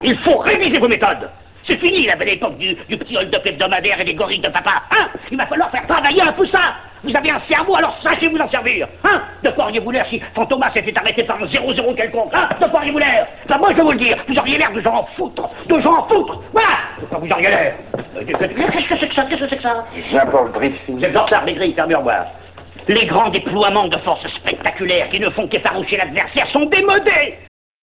extraits sonores du film